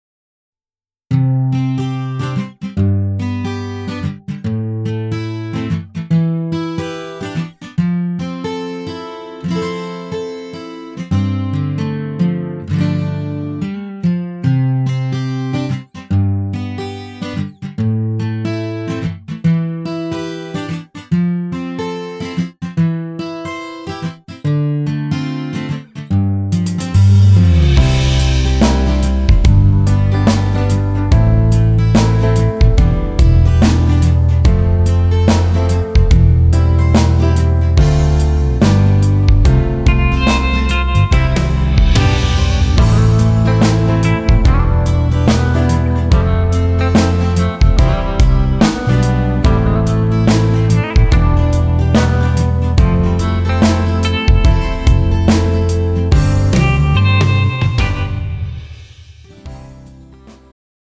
음정 원키
장르 축가 구분 Pro MR